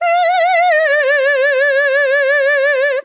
TEN VOCAL FILL 17 Sample
Tags: dry, english, female, fill, sample, TEN VOCAL FILL, Tension
POLI-VOCAL-Fills-100bpm-A-17.wav